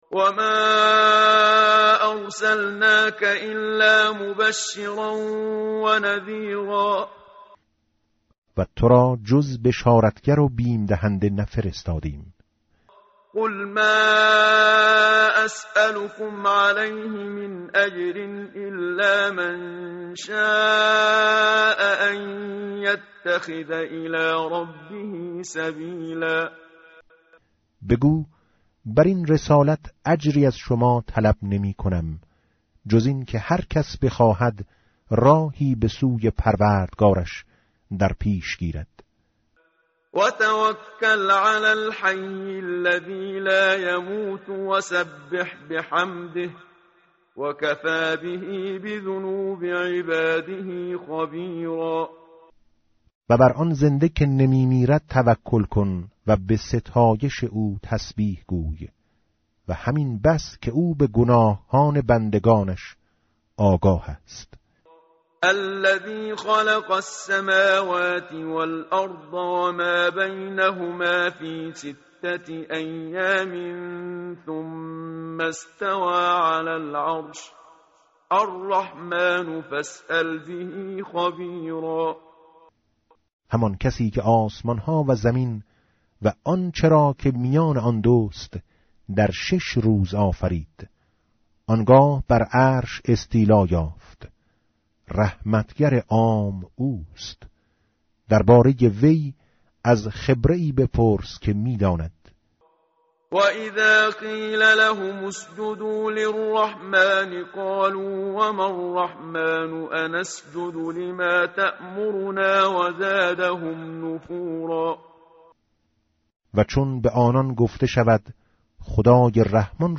tartil_menshavi va tarjome_Page_365.mp3